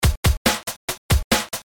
DrumNBass
描述：鼓和低音,鼓的节拍。
Tag: 175 bpm Drum And Bass Loops Drum Loops 296.27 KB wav Key : Unknown